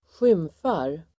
Uttal: [²sj'ym:far]